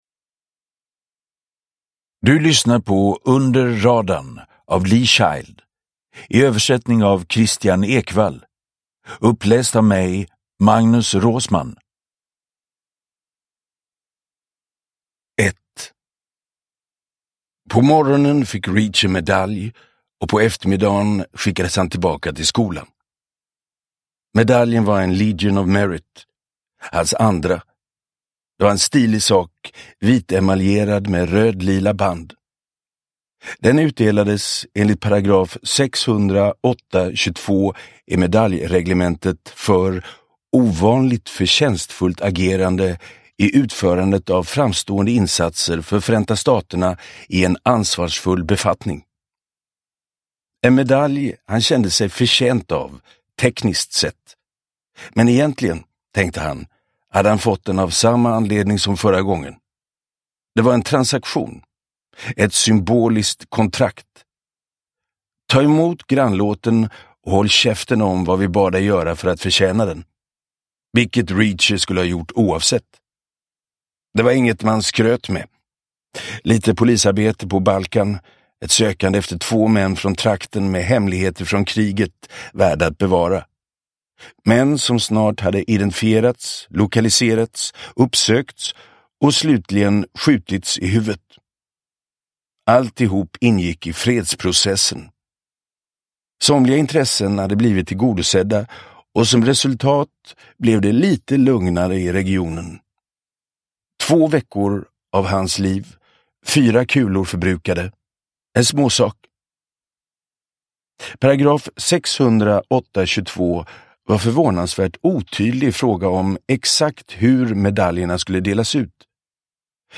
Under radarn – Ljudbok – Laddas ner
Uppläsare: Magnus Roosmann